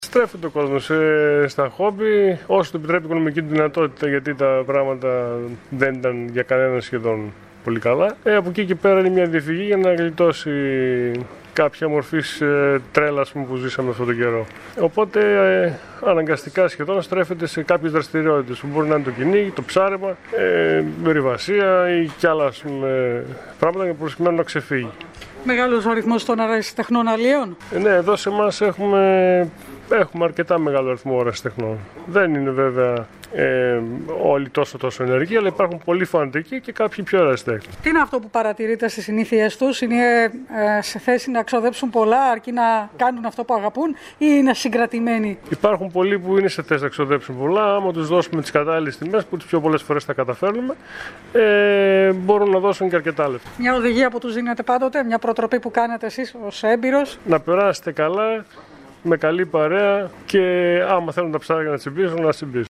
«Ο κόσμος στρέφεται στο χόμπι όσο του επιτρέπει η οικονομική του δυνατότητα και το καθημερινό του πρόγραμμα. Τα πράγματα δεν είναι τόσο καλά. Από εκεί και πέρα αναζητά τρόπους να γλιτώσει από όλα όσα ζήσαμε τον τελευταίο καιρό», δηλώνει ιδιοκτήτης καταστήματος αλιείας και προσθέτει ότι συνήθως αναζητούν διέξοδο στο ψάρεμα, στο κυνήγι, στην ορειβασία.